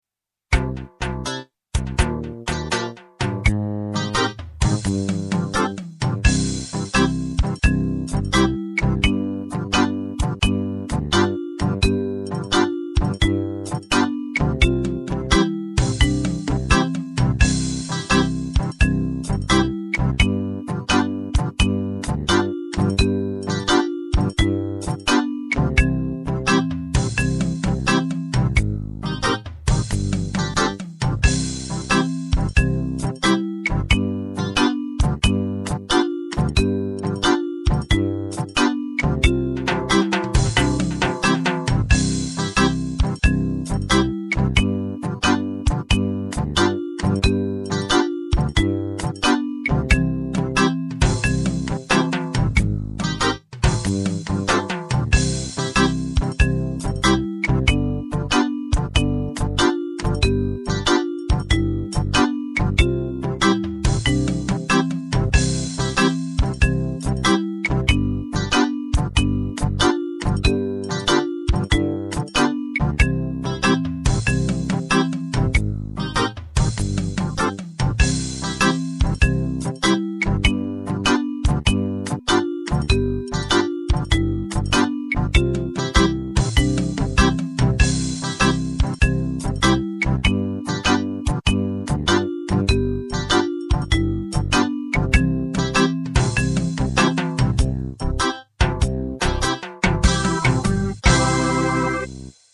Playbacks zum Mitspielen mit der Harp zu einigen Musikstücken vom Kurs:
Download "Harp-Reggae" Playback Playback "Harp-Reggae" in C-Dur, etwa schneller werdend.
Playback - Harp-Reggae.mp3